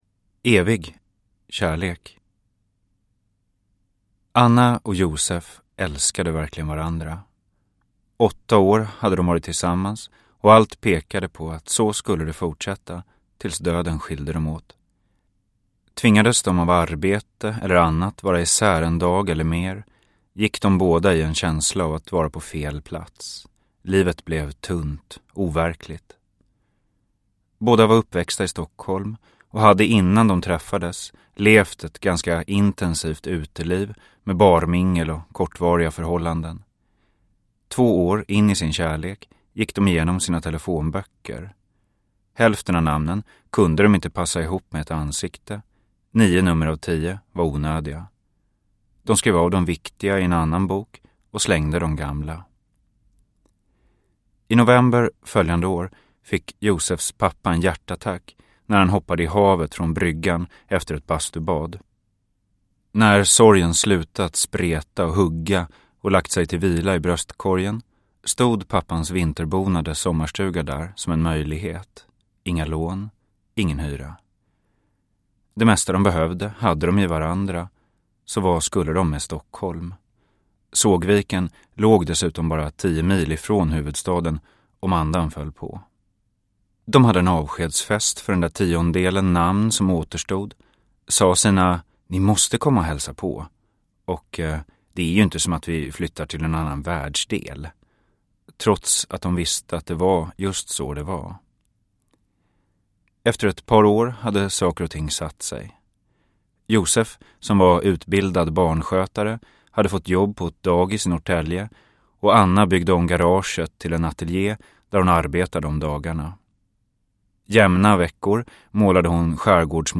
Evig kärlek – Ljudbok – Laddas ner